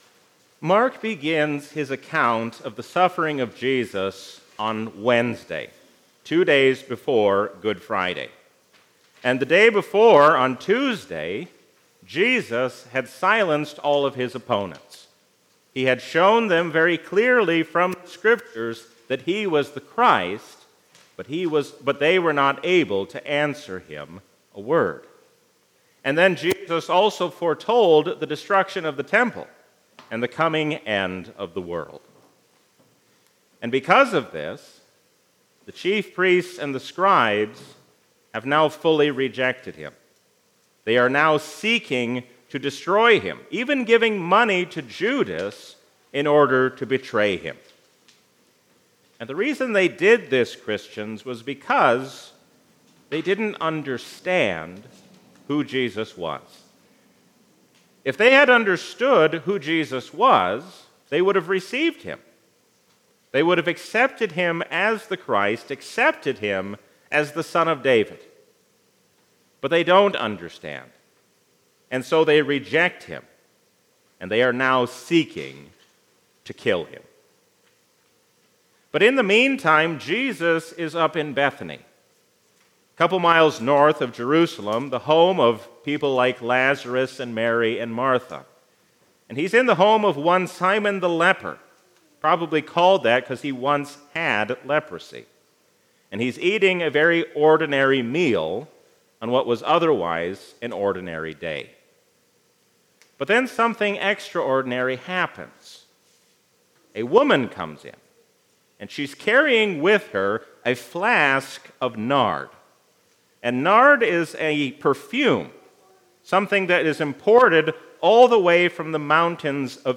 A sermon from the season "Lent 2022." Let us love, think, and act as one, because we are one in Christ.